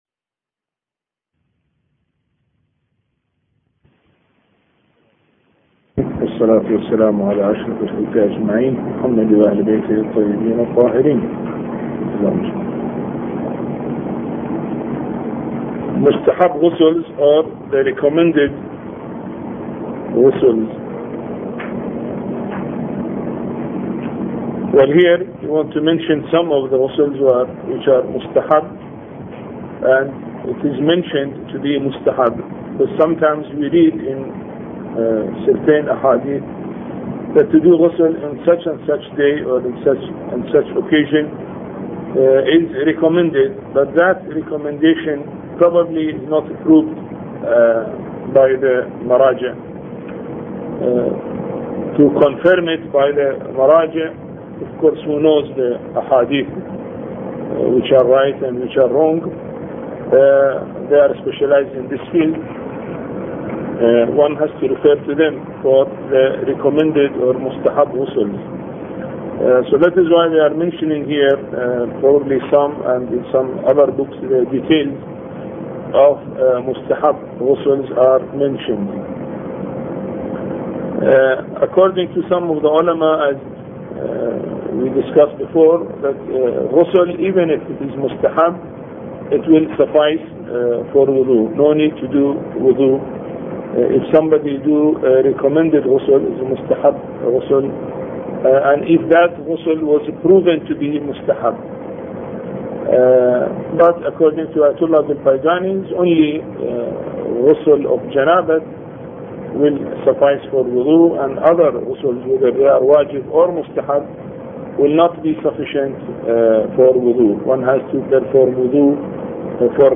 A Course on Fiqh Lecture 11